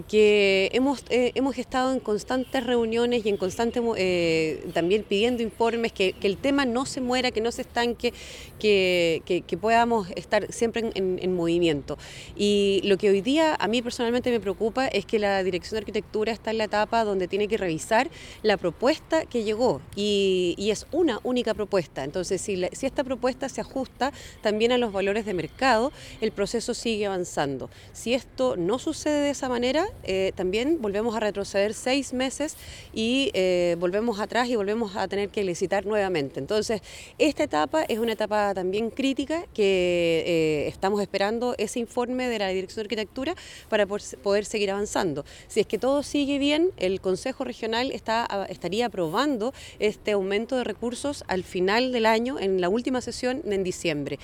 La Consejera Regional, Andrea Iturriaga señaló que es preocupante solo tener una empresa en proceso de selección, pues si no se ajusta se deberá iniciar nuevamente la licitación, y en caso de ser favorable estos recursos podrían aprobarse recién en el mes de diciembre.